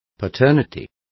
Complete with pronunciation of the translation of paternities.